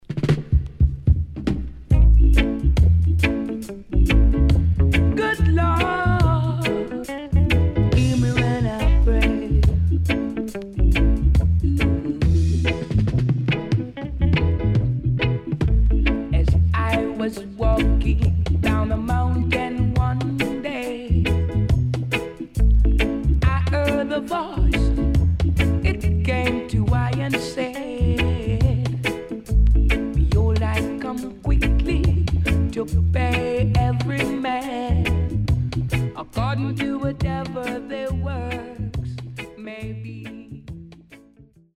Good Vocal